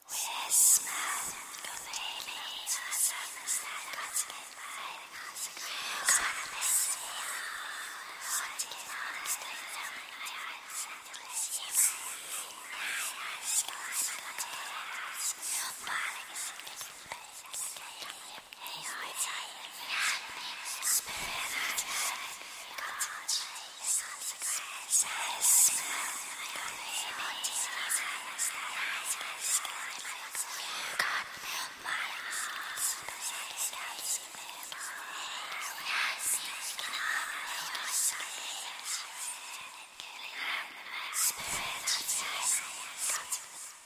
Звук, где призрак шепчется в темноте сам с собой
zvuk-gde-prizrak-shepchetsia-v-temnote-sam-s-soboi.mp3